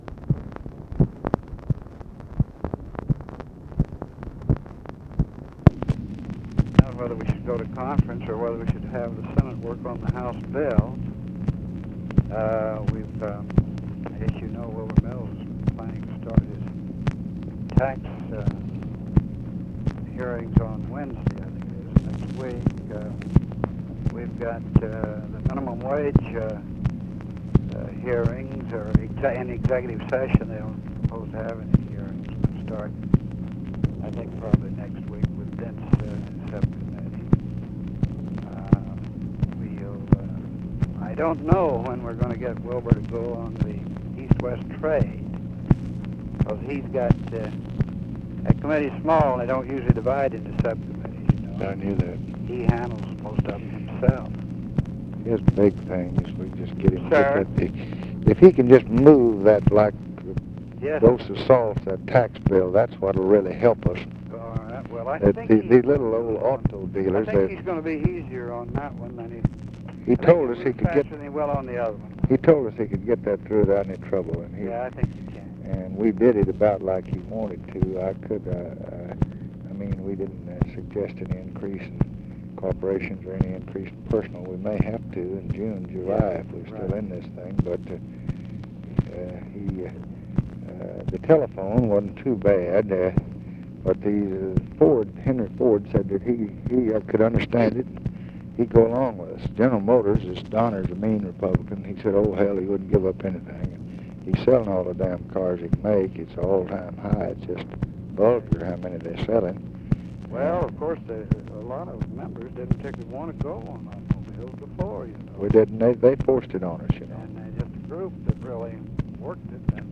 Conversation with CARL ALBERT, January 14, 1966
Secret White House Tapes